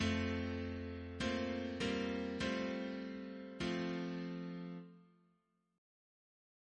3rd voice is for Pedal held throughout Reference psalters